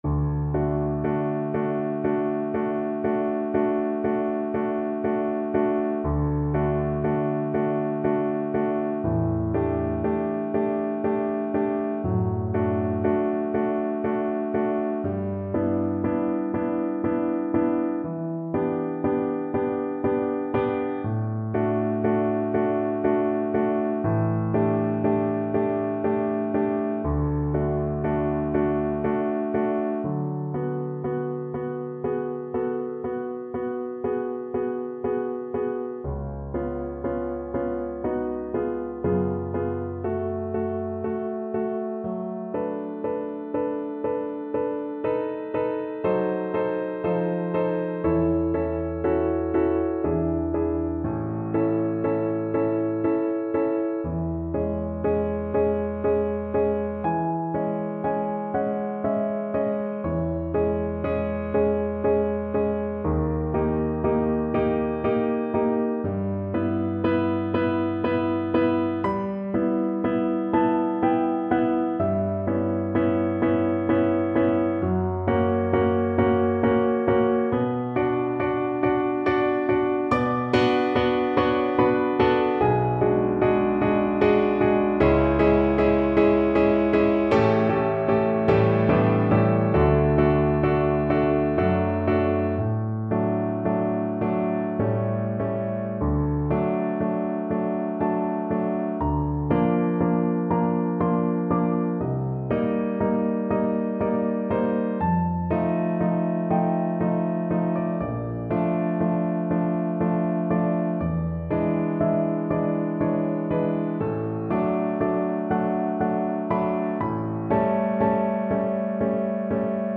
Cello version
3/4 (View more 3/4 Music)
Andante (=c.60)
Classical (View more Classical Cello Music)